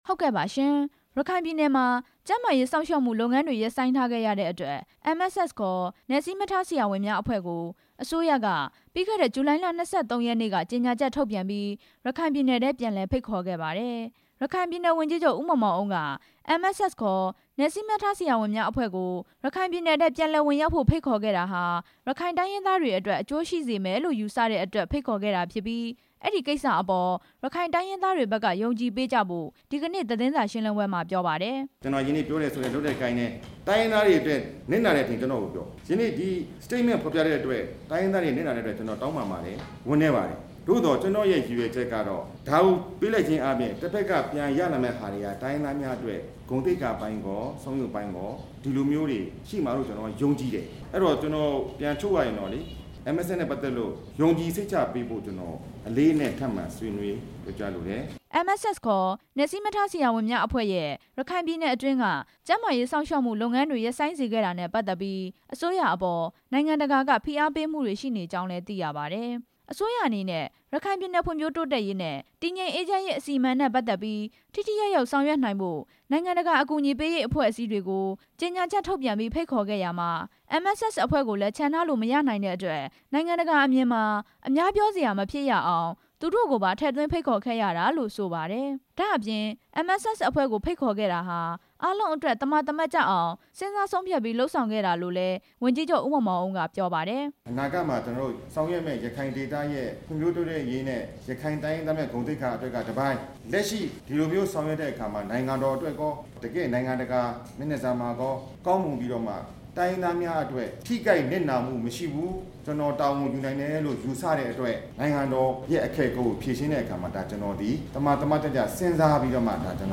ရခိုင်ပြည်နယ်ဝန်ကြီးချုပ်ရဲ့ သတင်းစာရှင်းလင်းပွဲ
ရခိုင်ပြည်နယ်အစိုးရအဖွဲ့ရုံးမှာ ဒီနေ့ ကျင်းပတဲ့ သတင်းစာရှင်းလင်းပွဲမှာ ဝန်ကြီးချုပ်က အခုလို ပြောလိုက်တာပါ။